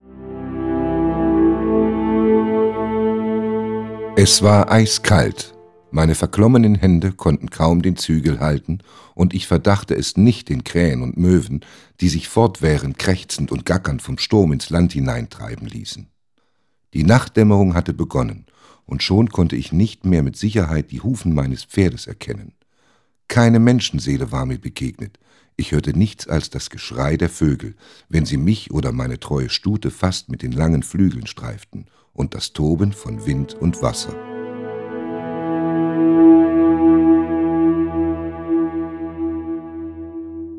– Theodor Storm: Der Schimmelreiter (Gesprochen:)